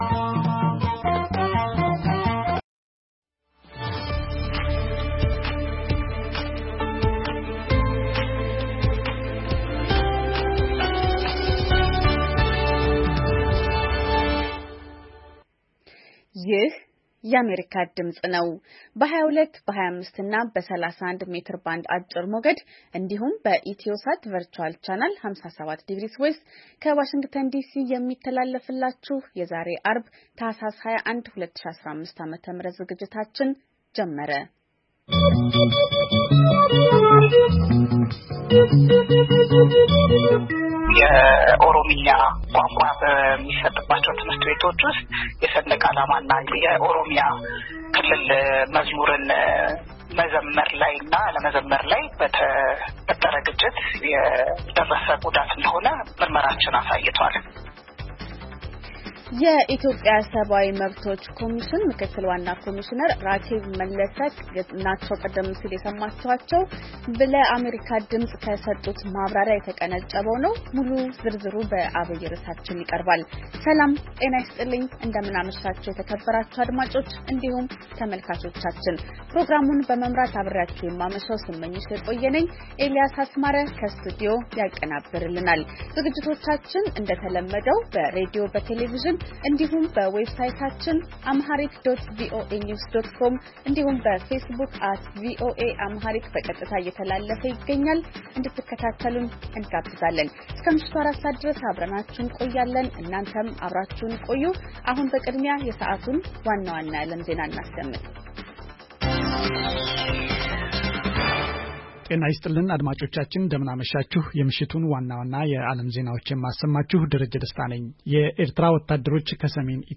ዐርብ፡-ከምሽቱ ሦስት ሰዓት የአማርኛ ዜና